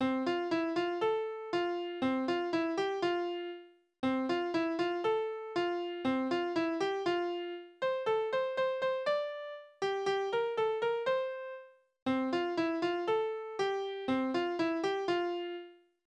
« 6317 » Use Katt hät Küken. Tierverse: Die Katze Use Katt hät Küken.
Tonart: F-Dur Taktart: 2/4 Tonumfang: große None Externe Links